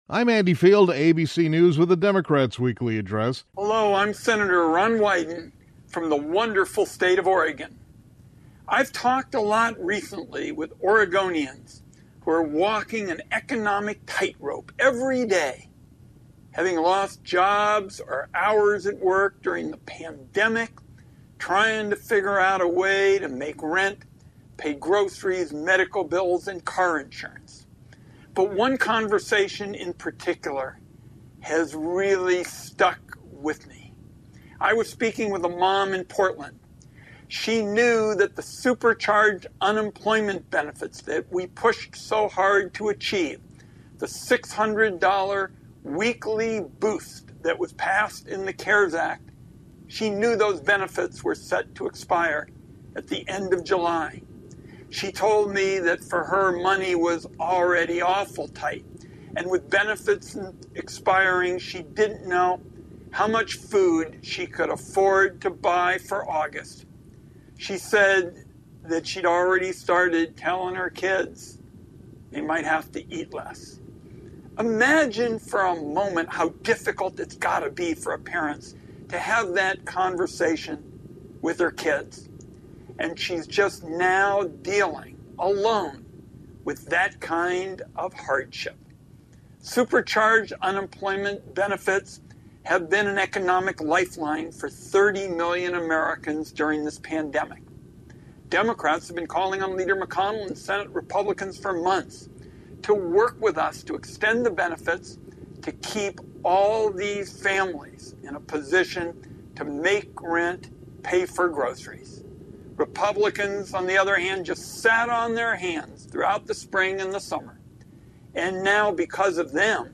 During the Democratic Weekly Address, Sen. Ron Wyden (D-OR) stated that the coronavirus relief proposal by Senate Republicans is heartless.
Wyden was Tuesday’s KVML “Newsmkaer of the Day”.